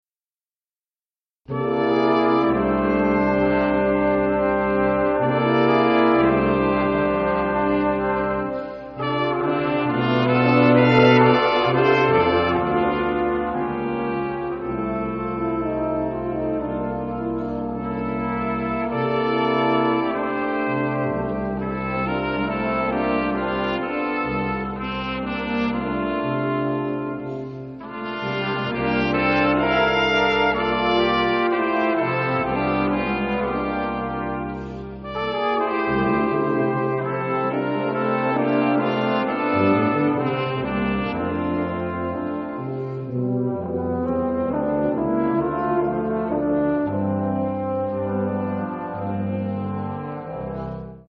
St. Rose Concert Series 2006
Polished Brass
St. Rose Catholic Church